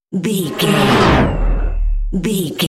Sci fi big vehicle whoosh
Sound Effects
futuristic
whoosh